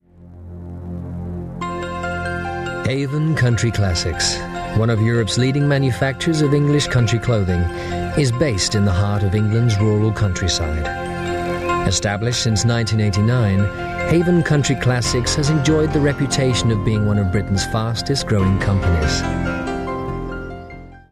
english speaker, englischer sprecher, mittlere Stimme
Sprechprobe: Industrie (Muttersprache):
Warm voice, very flexible for animation and character work.